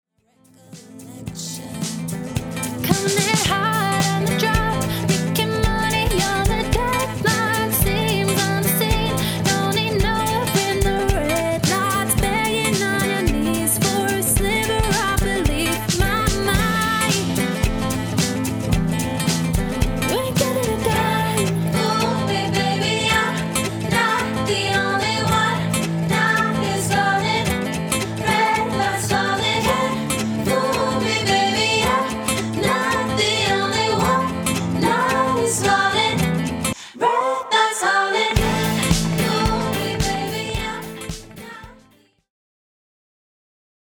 Indie/Edgy